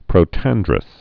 (prō-tăndrəs)